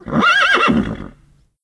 c_whorse_bat1.wav